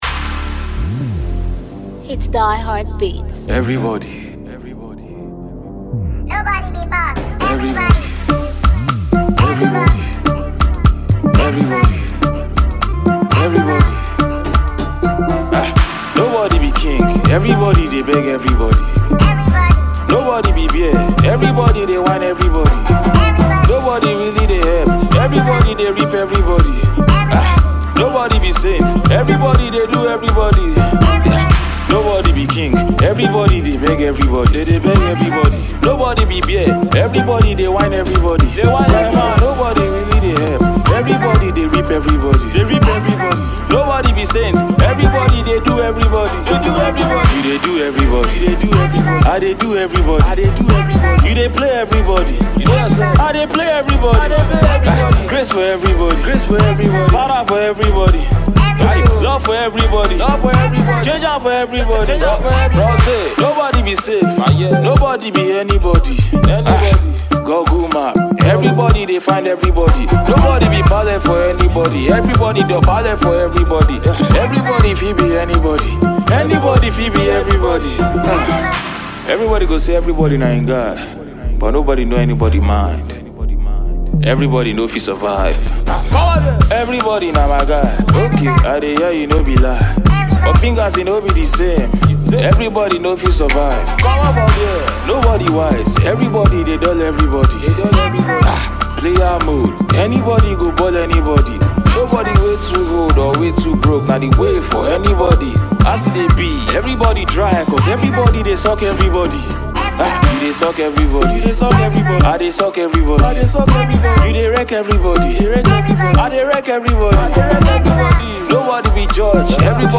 Nigerian talented rapper